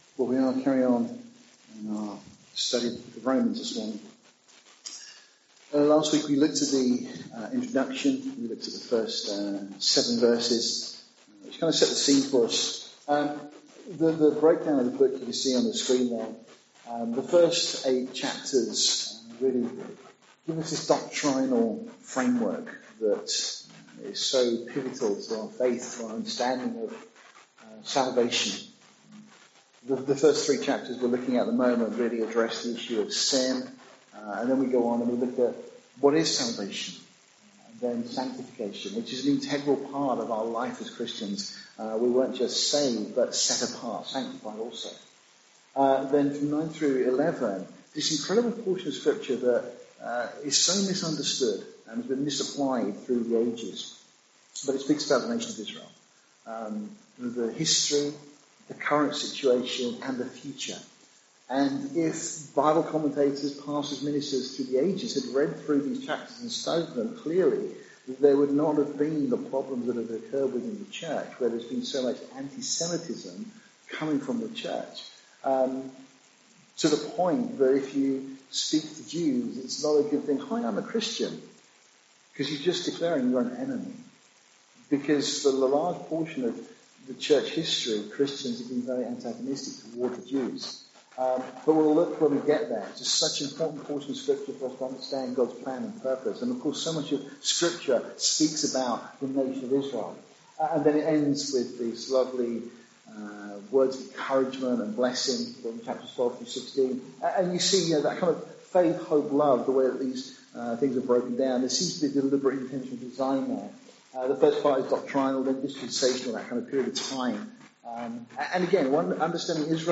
May you be blessed and encouraged by this teaching.